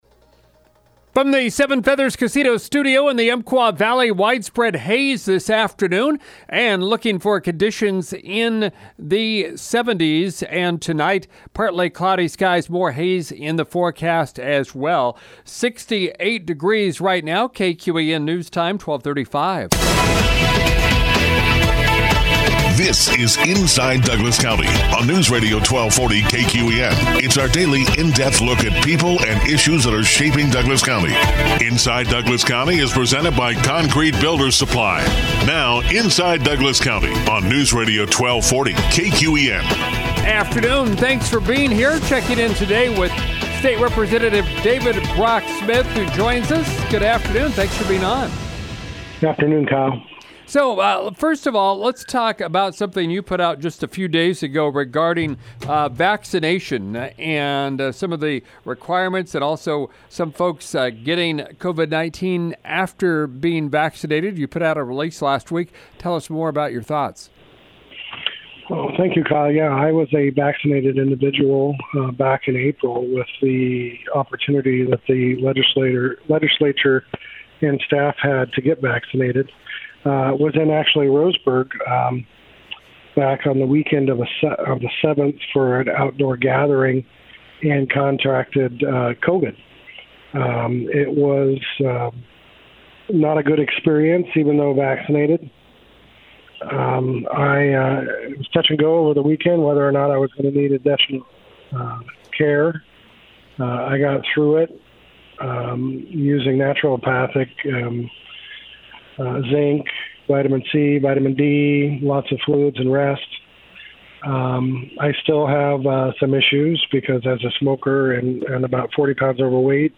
State Representative David Brock Smith talks about vaccine mandates, getting COVID-19 after being vaccinated, redistricting and other legislative topics.